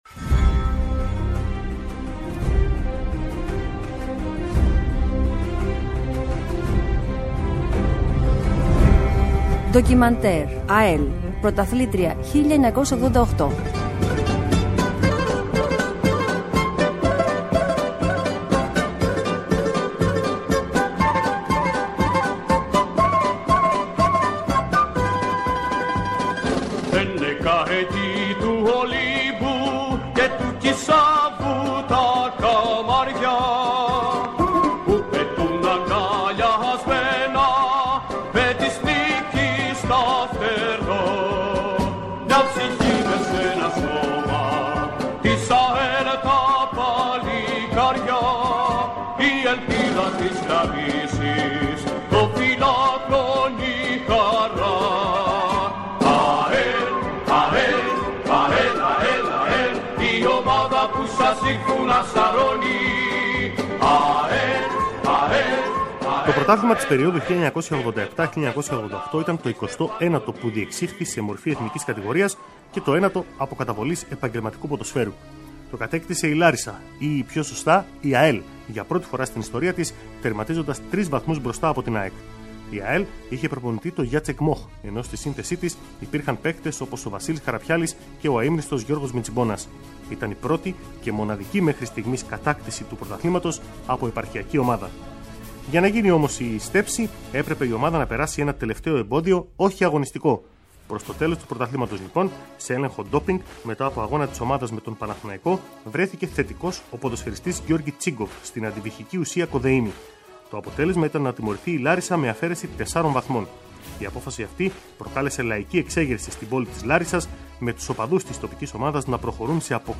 Μια εκπομπή με ιστορικές αναφορές σε πρόσωπα και γεγονότα που σημάδεψαν τον αθλητισμό εντός κι εκτός Ελλάδας. Έρευνα και σπάνια ηχητικά ντοκουμέντα, σε μια σειρά επεισοδίων από την ΕΡΑσπορ.
Το πρώτο και μοναδικό μέχρι σήμερα πρωτάθλημα της ΑΕΛ, όπως παρουσιάζεται σε ένα ακόμη επεισόδιο της σειρά ντοκιμαντέρ της ΕΡΑ ΣΠΟΡ. Σπάνια ηχητικά ντοκουμέντα, ενώ μιλούν και οι πρωταγωνιστές εκείνης της εποχής.